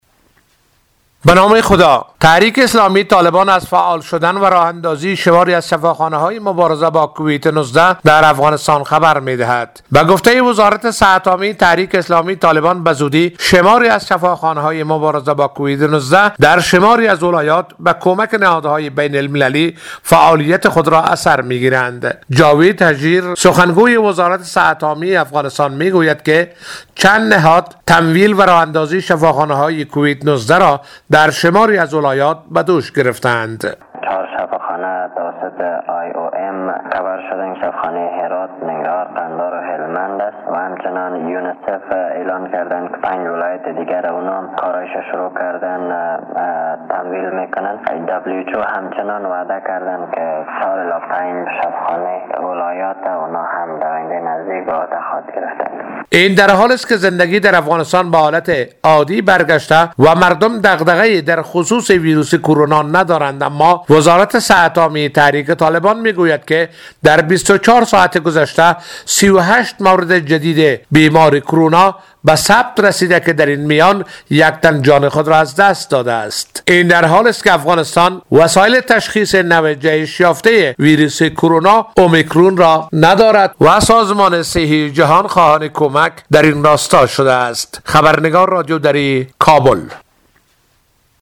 گزارش
از کابل